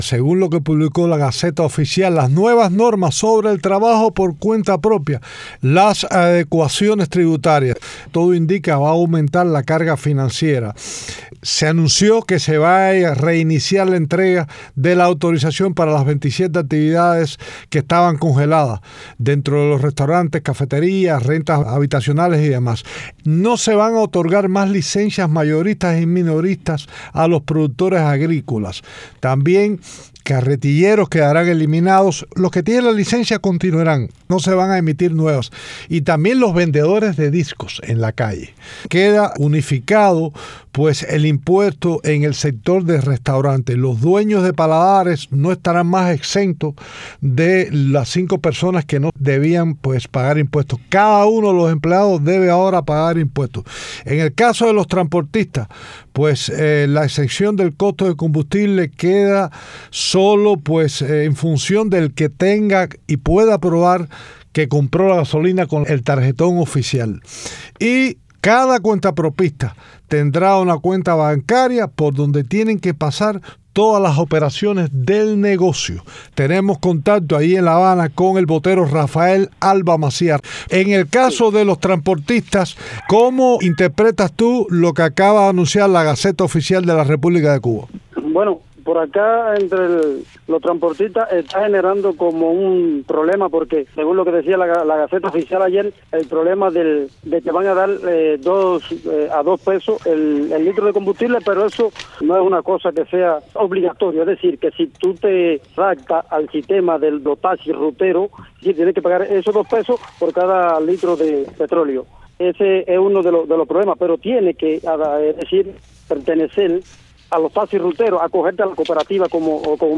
Radio Martí entrevistó a varios trabajadores sobre su experiencia en el sector privado y el reto que imponen las nuevas regulaciones.